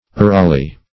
urali - definition of urali - synonyms, pronunciation, spelling from Free Dictionary Search Result for " urali" : The Collaborative International Dictionary of English v.0.48: Urali \U"ra*li\, n. [See Wourali .]